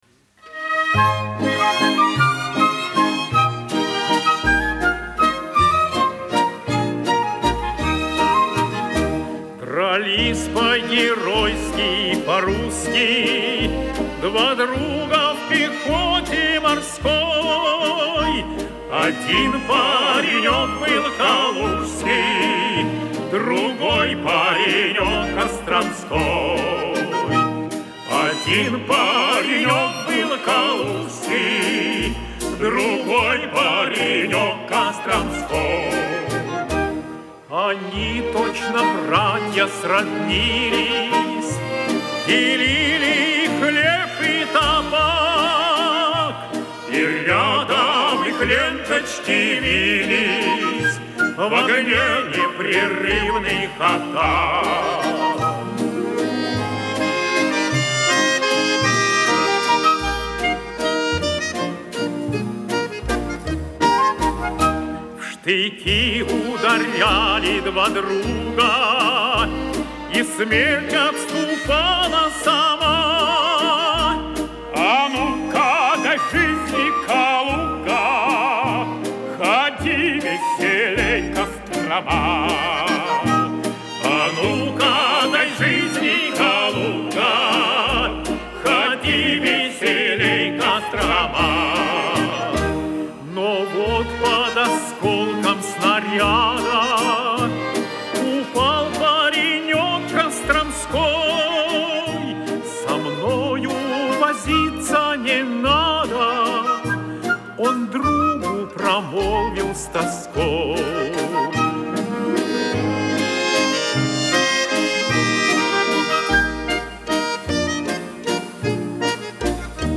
Радиопередача